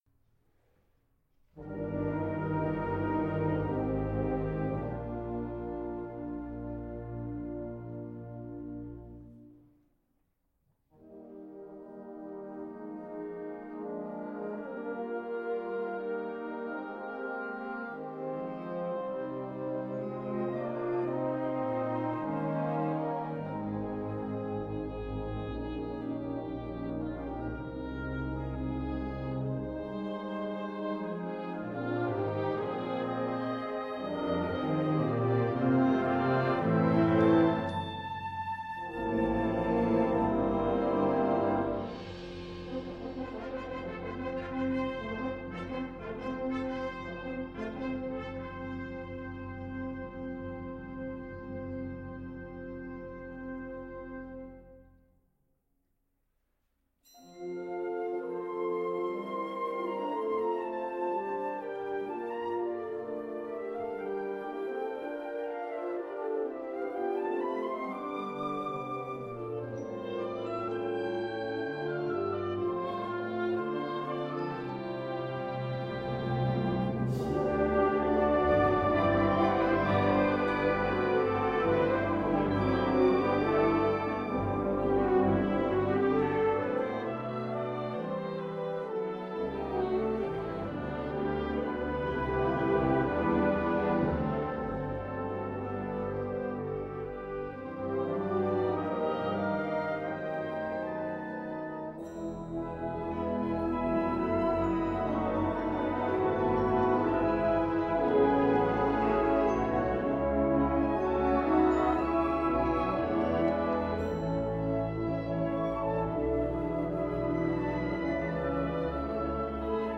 A Period Piece for Bands of Winds